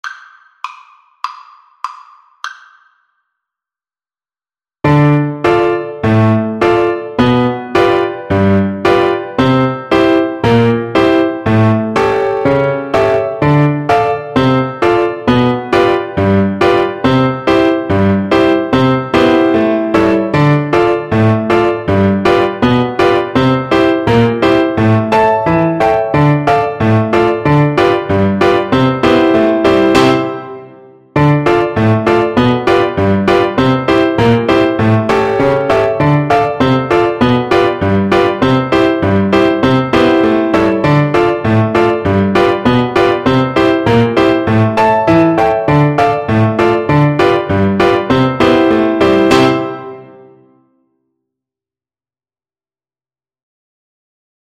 Flute
C major (Sounding Pitch) (View more C major Music for Flute )
First time accel. throughout
2/2 (View more 2/2 Music)
Traditional (View more Traditional Flute Music)
Gypsy music for flute